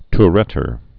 (t-rĕtər)